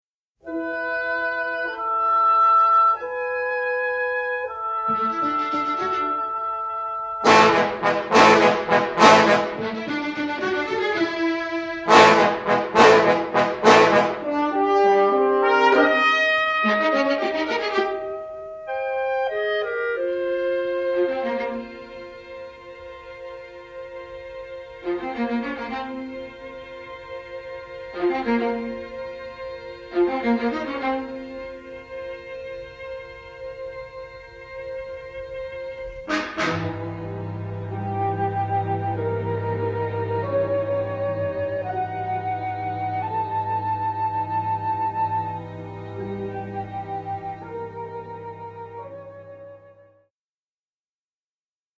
western score
complete score mastered in mono from print takes